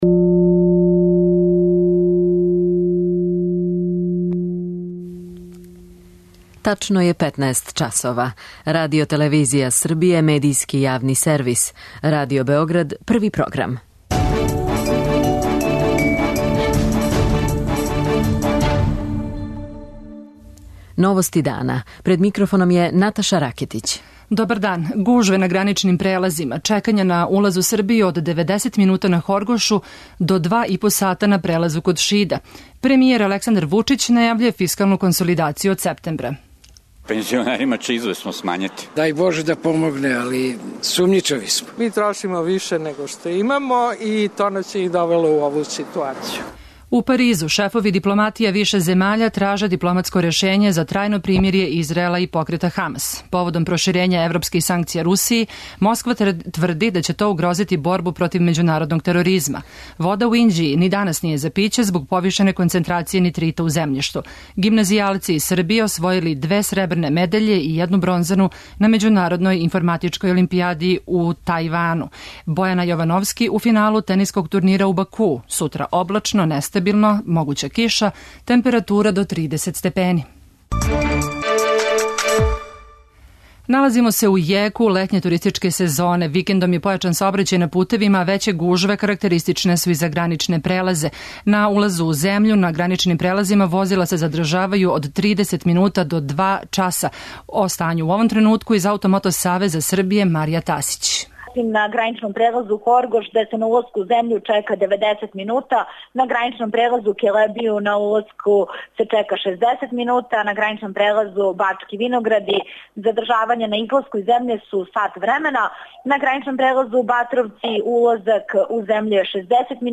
Због великих врућина, на граничним прелазима са најдужим задржавањем возила, у току дана ће бити допремљене и хладњаче са водом. Наши репортери извештавају о ситуаци на Хоргошу и на наплатној рампи Наис.